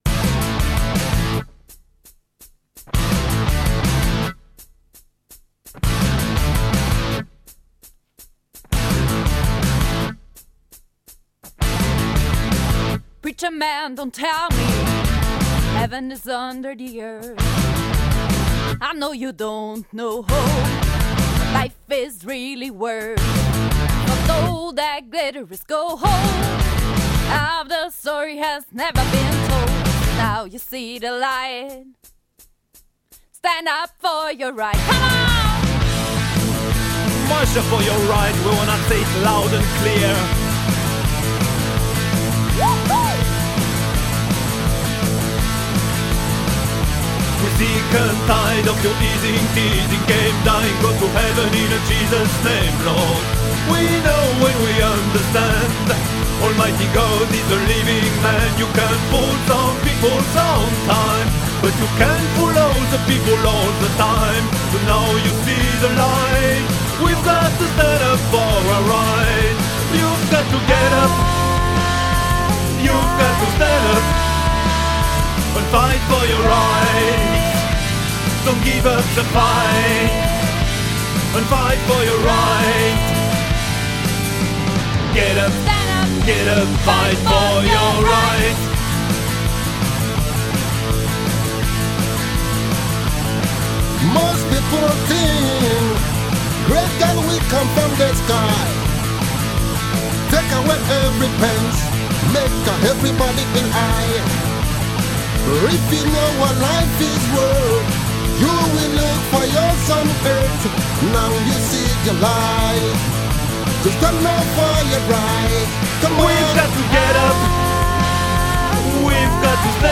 live Bootleg / Bastard Pop / Mashups